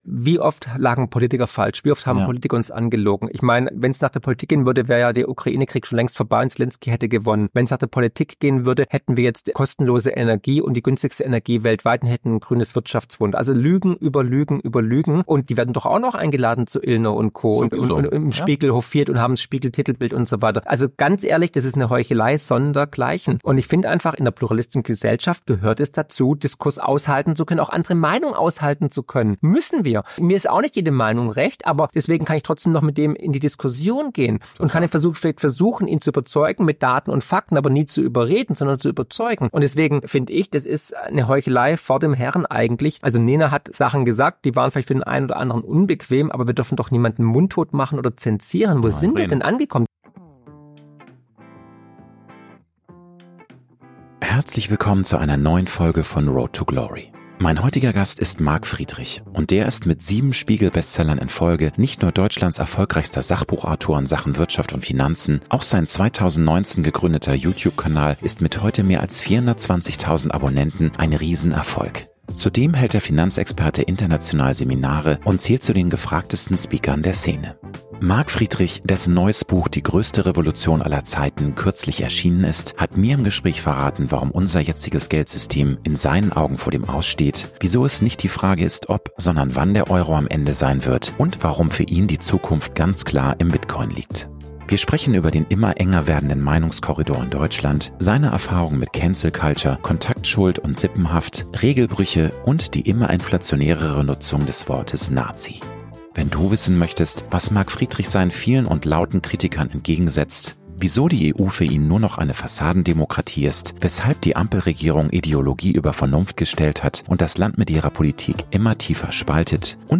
Mein heutiger Gast ist Marc Friedrich.